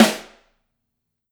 Rim07_2.wav